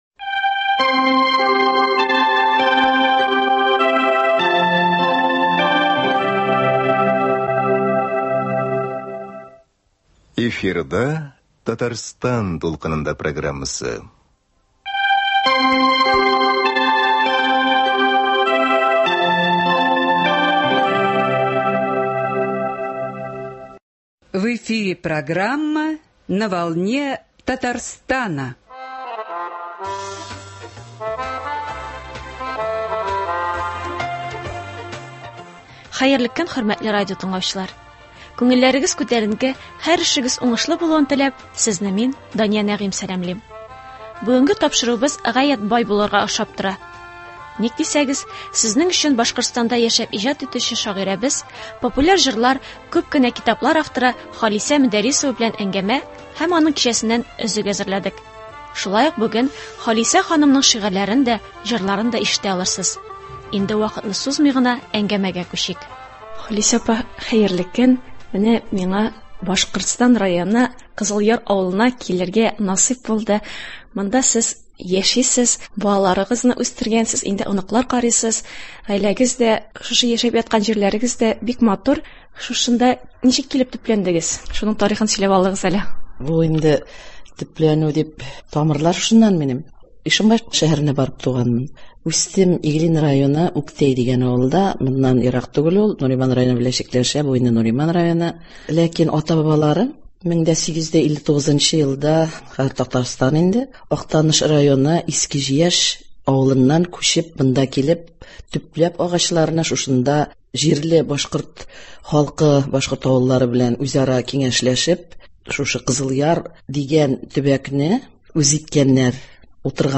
Студия кунагы